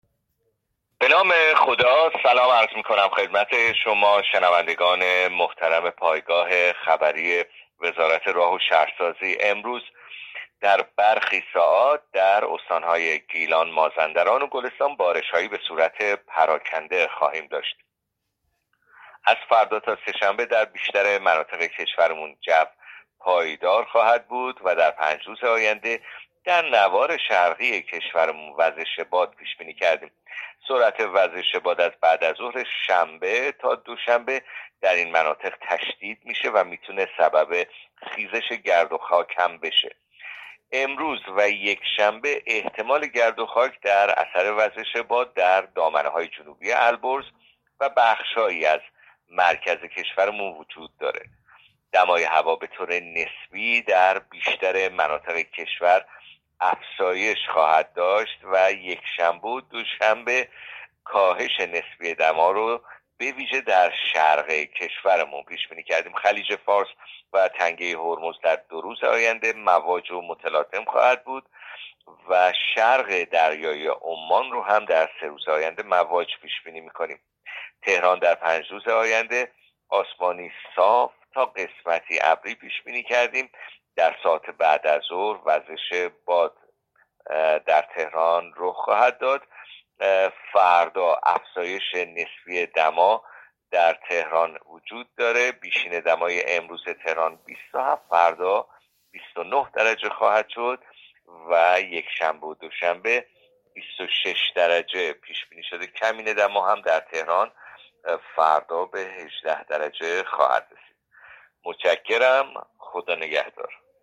گزارش رادیو اینترنتی پایگاه‌ خبری از آخرین وضعیت آب‌وهوای یازدهم مهر؛